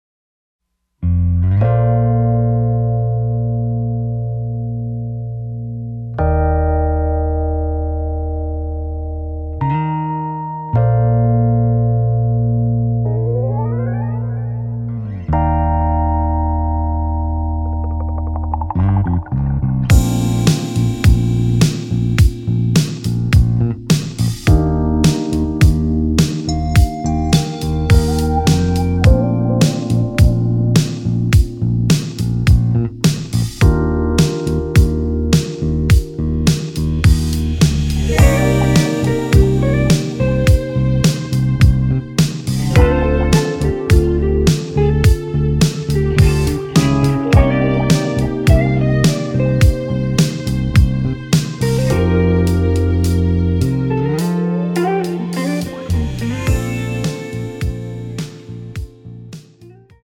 Bb
앞부분30초, 뒷부분30초씩 편집해서 올려 드리고 있습니다.
중간에 음이 끈어지고 다시 나오는 이유는